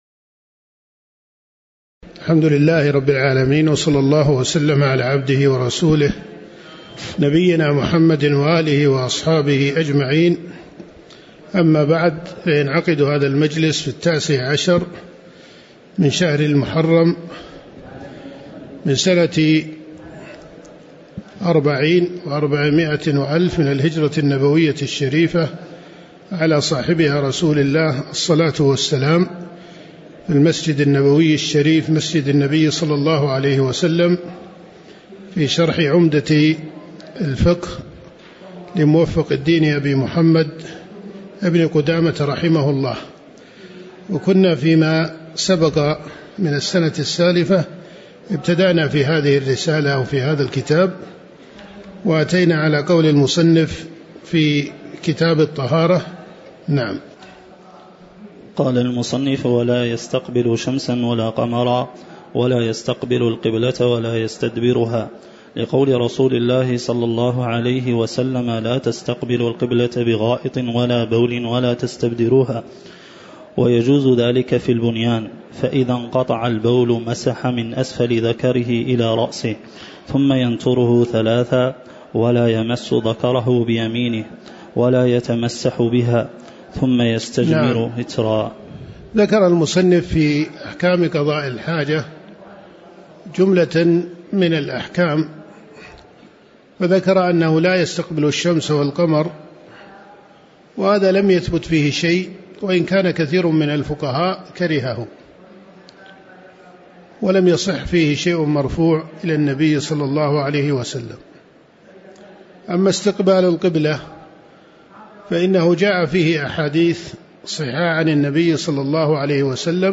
تاريخ النشر ١٩ محرم ١٤٤٠ هـ المكان: المسجد النبوي الشيخ: معالي الشيخ د. يوسف بن محمد الغفيص معالي الشيخ د. يوسف بن محمد الغفيص من قول المصنف ولا يستقبل شمساً ولا قمراً (01)